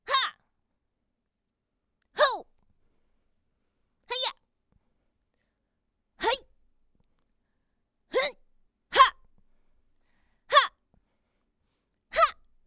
女激励2.wav
女激励2.wav 0:00.00 0:12.68 女激励2.wav WAV · 1.1 MB · 單聲道 (1ch) 下载文件 本站所有音效均采用 CC0 授权 ，可免费用于商业与个人项目，无需署名。
人声采集素材/女激励/女激励2.wav